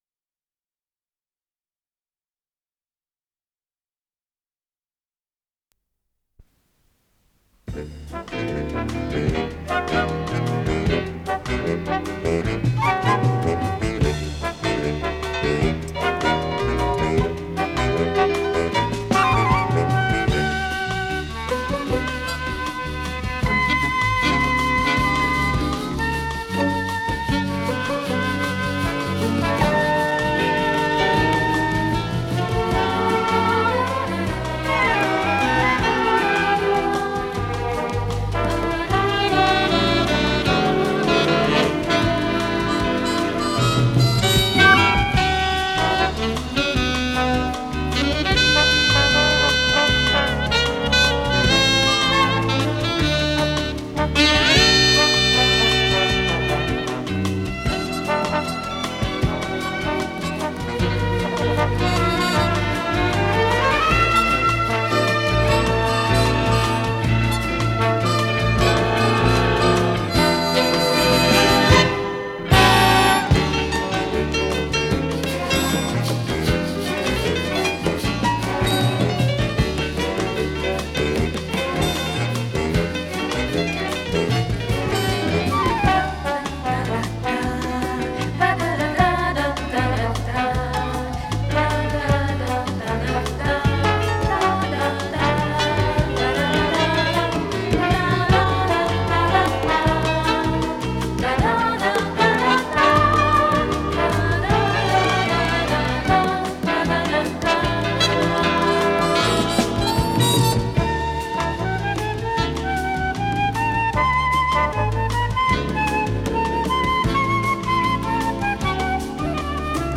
с профессиональной магнитной ленты
Вокальное трио
ВариантДубль моно